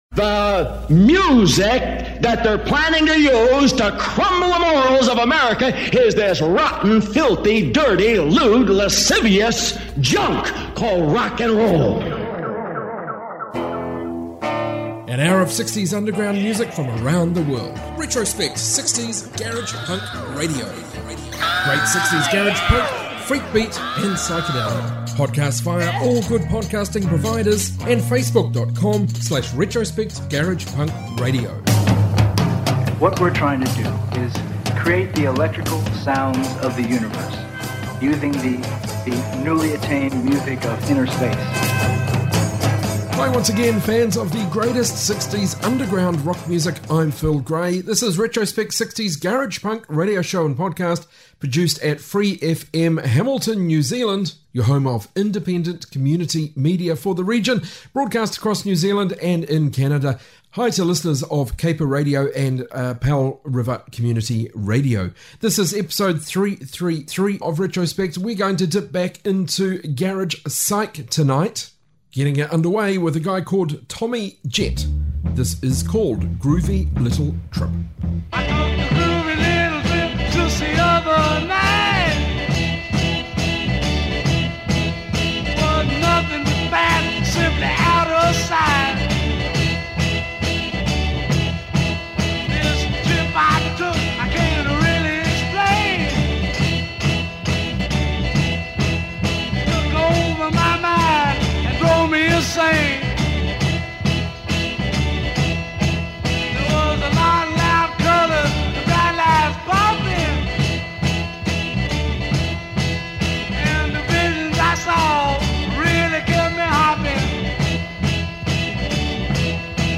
60s proto-psych